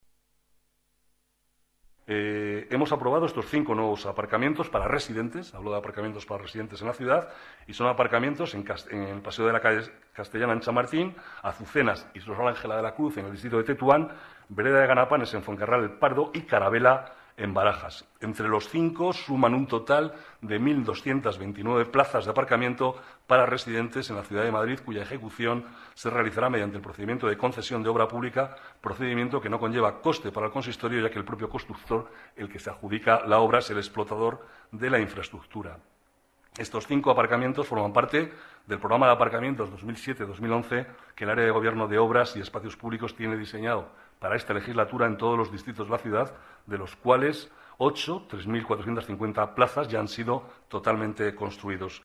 Nueva ventana:Declaraciones del vicealcalde, Manuel Cobo: Cinco nuevos aparcamientos para residentes